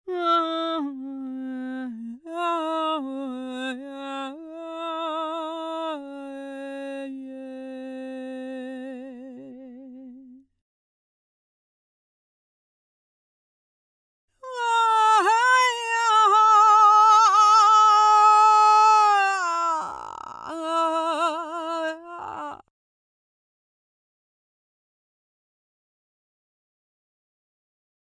Mit dem Tverb-Preset von Tony Visconti und ohne jegliche Anpassung der Parameter hört es sich so an:
Die Stimme wirkt deutlich voller und durchsetzungsfähiger, vom Hall ist allerdings nichts zu hören.